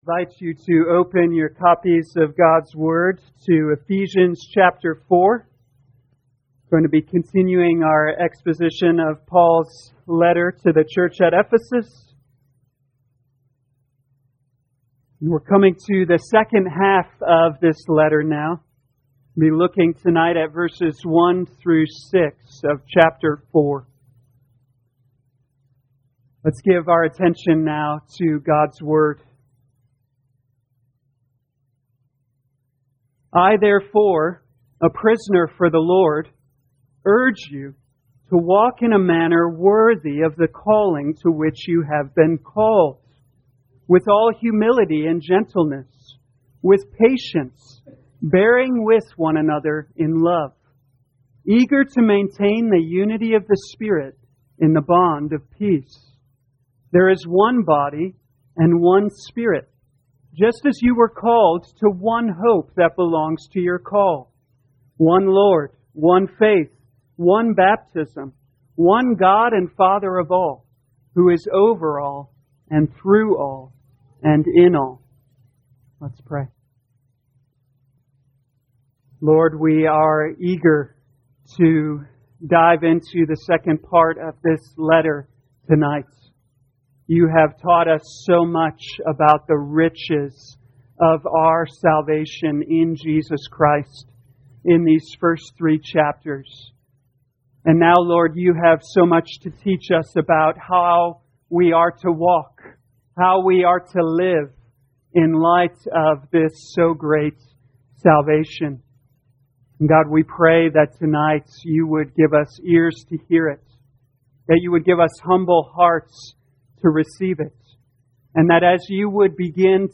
2020 Ephesians Evening Service Download